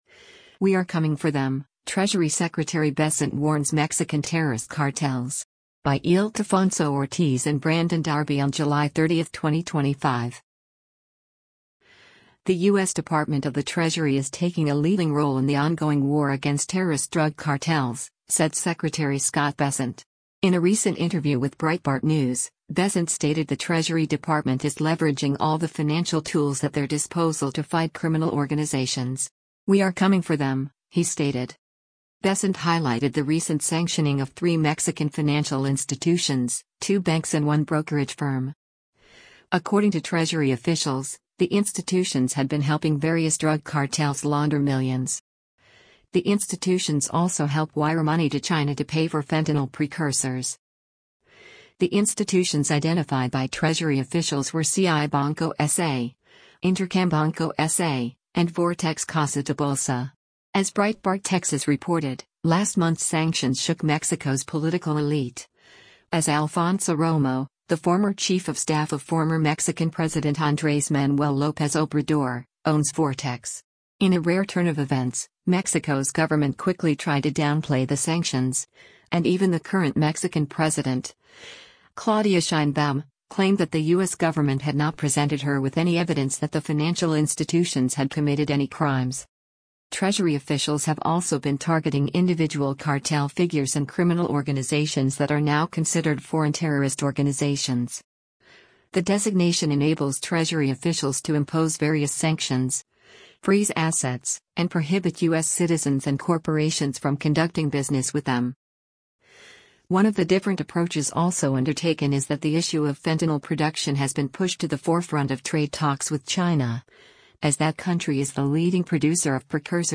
In a recent interview with Breitbart News, Bessent stated the Treasury Department is leveraging all the financial tools at their disposal to fight criminal organizations.